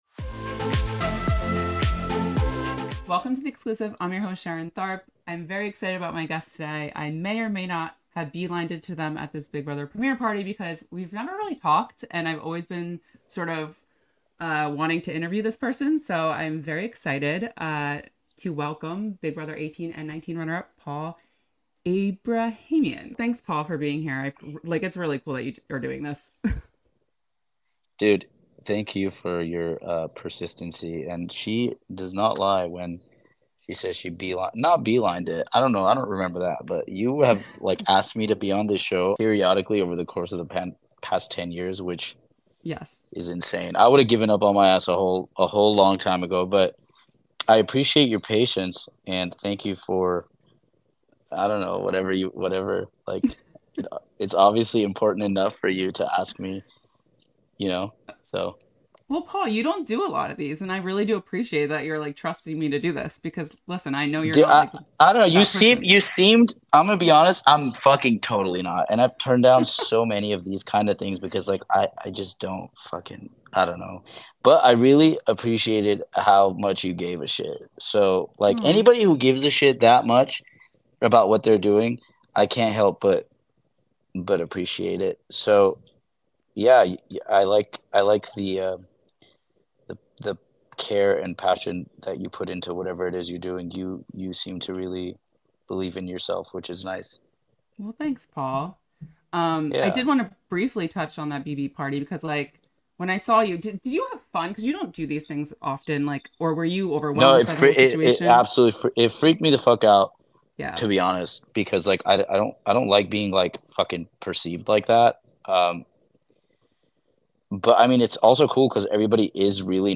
In a new interview